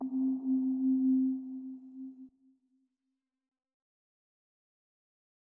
Synth Pluck (R.I.P. SCREW)(1).wav